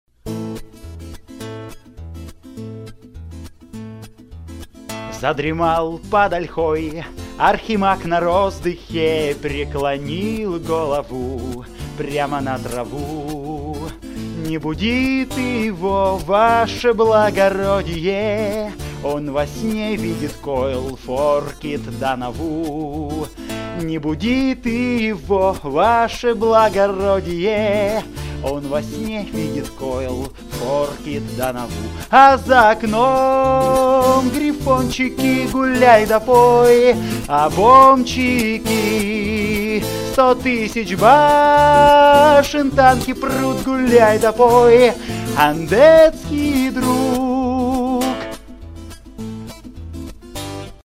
песенку моно было сделать более информативной, и мелодичной